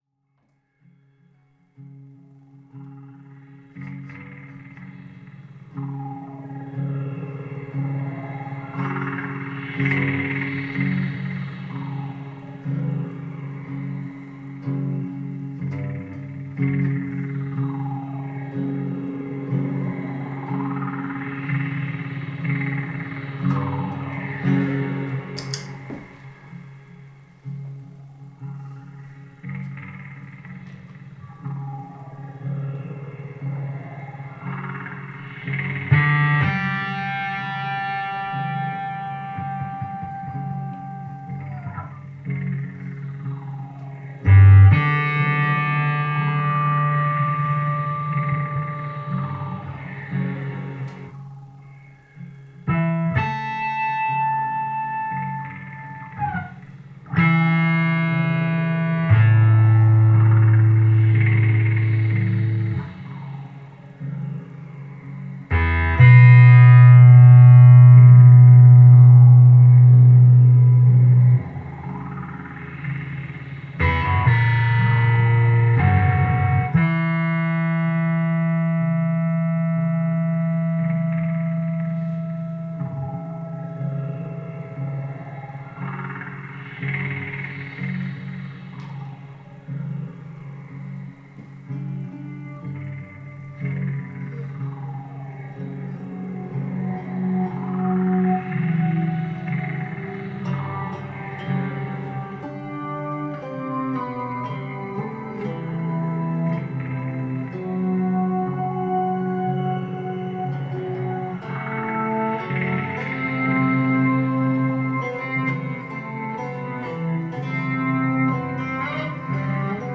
all instruments